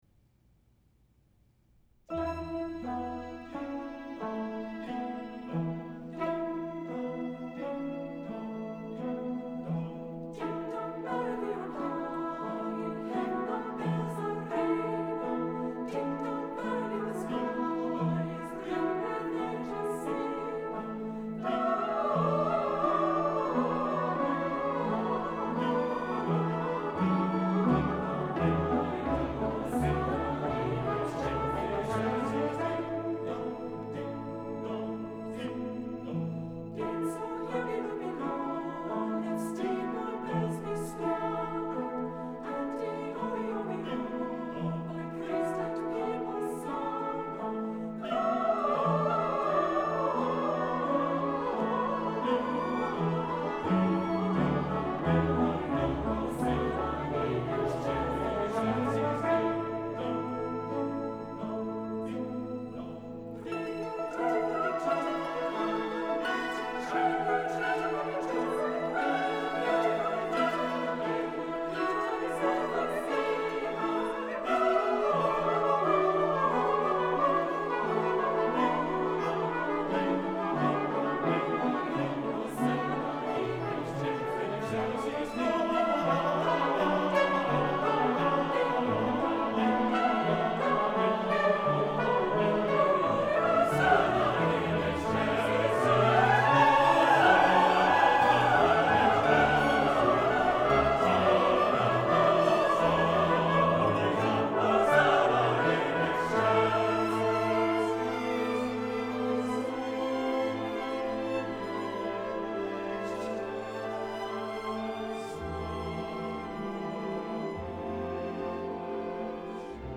• Trumpet 1 and 2
• Horn
• Trombone
• Tuba
• Harp
• Violin I
• Viola
• Cello
• Double Bass
• SATB Choir with divisi